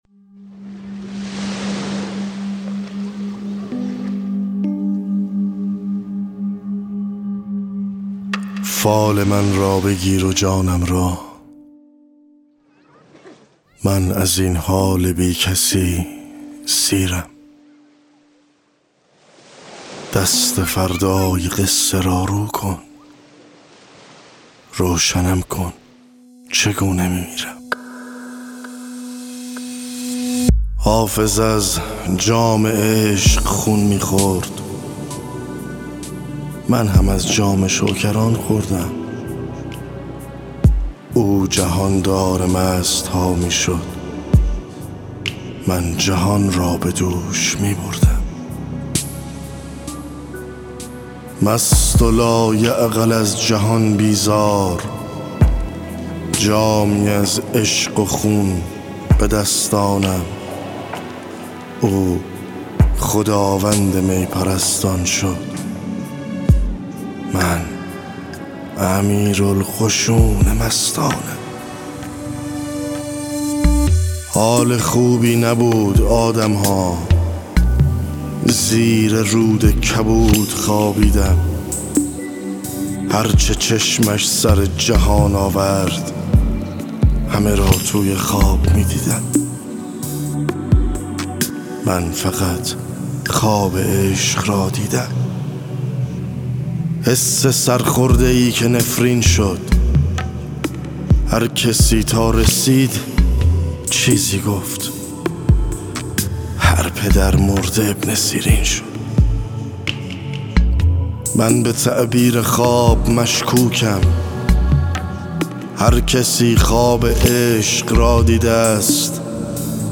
دانلود دکلمه مدار مربع با صدای علیرضا آذر